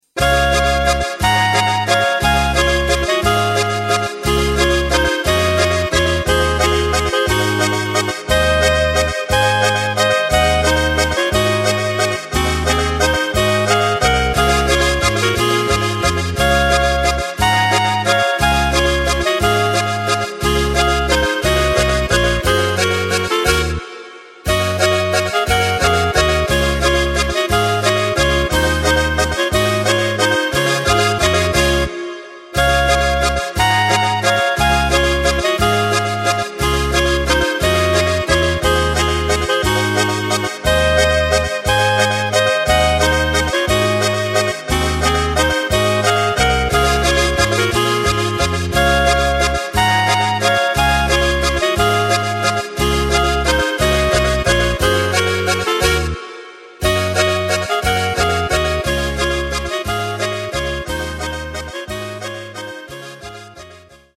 Takt:          3/4
Tempo:         178.00
Tonart:            C
Schweizer Walzer-Ländler!
Playback mp3 Demo